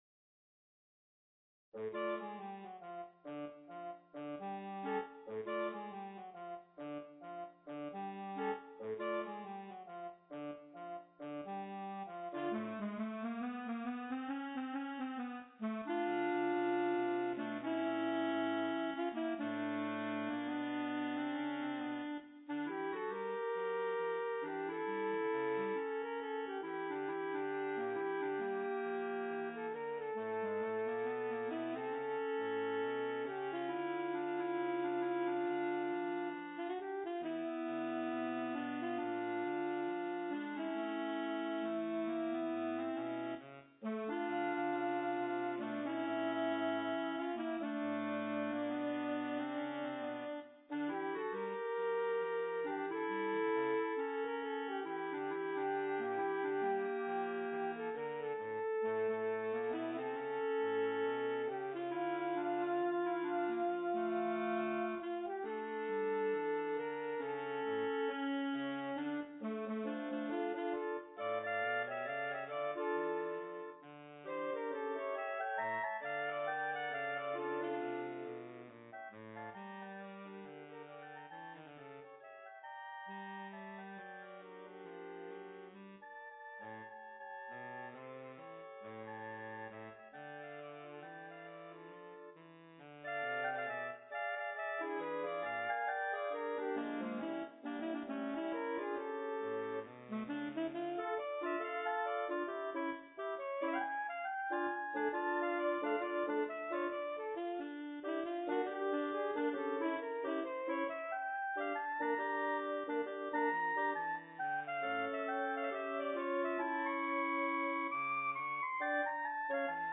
B♭ Clarinet 1 B♭ Clarinet 2 B♭ Clarinet 3 Bass Clarinet
单簧管四重奏
流行 , 爵士
希望您能通过单簧管的音色，感受这首经典歌曲在爵士风格下的独特魅力。